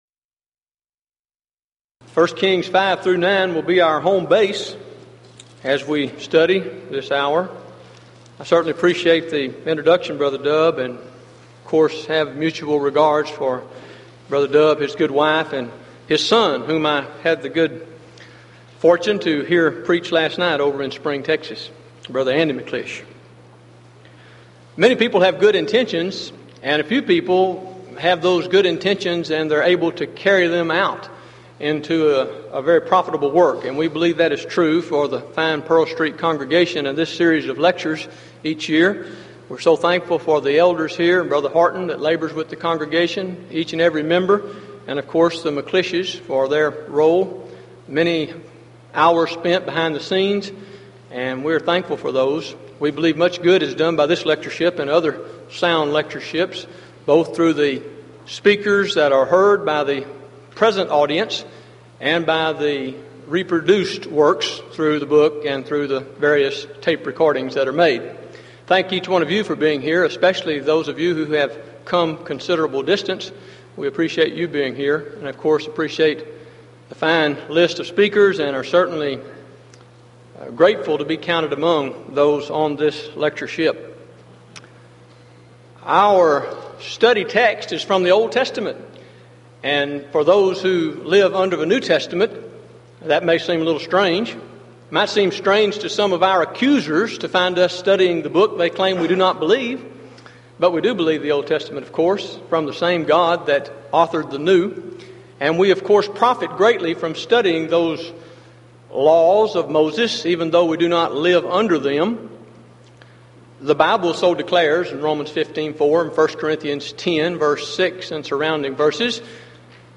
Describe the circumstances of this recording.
Event: 1993 Denton Lectures Theme/Title: Studies In I & II Kings, I & II Chronicles